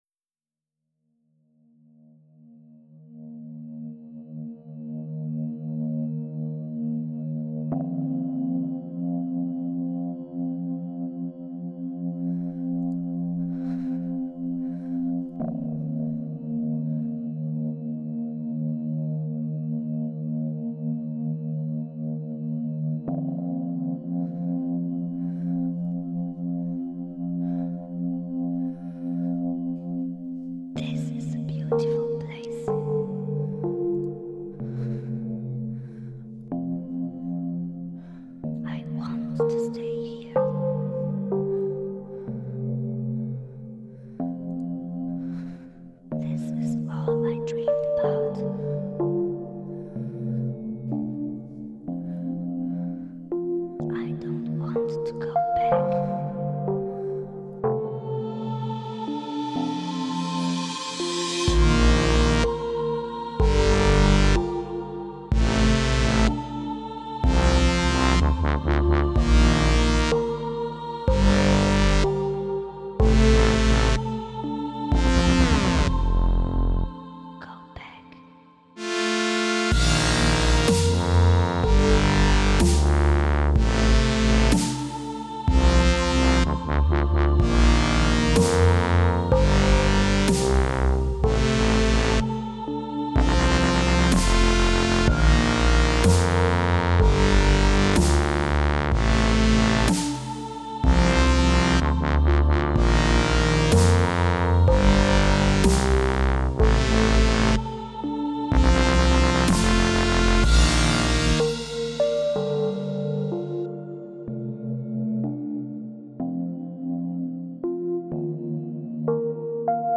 Halb - fertiges Dubstep /Chillstep
Ich glaub, es beißt sich am ende kurz der Bass mit dem Lead, aber weiß es nicht...